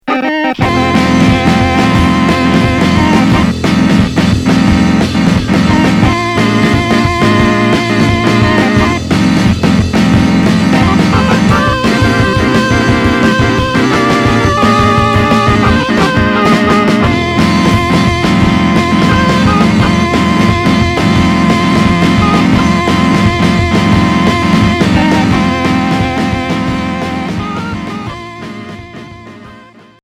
Garage trash core Troisième 45t retour à l'accueil